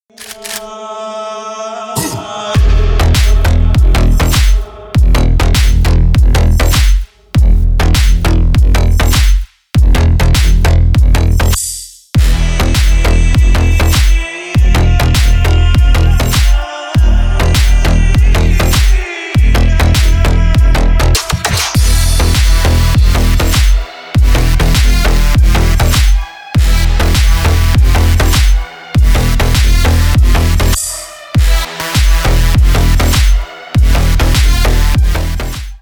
Танцевальные
клубные # громкие # без слов